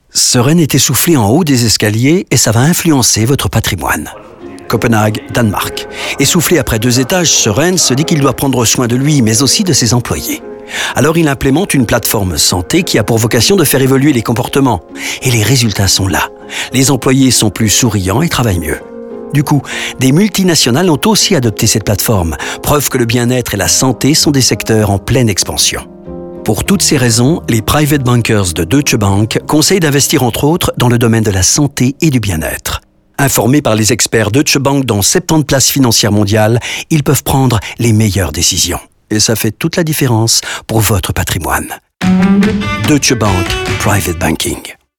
Nous prenons également le temps de développer les histoires de Rinku, Søren, Juan et Edward dans des spots radio de 45 secondes.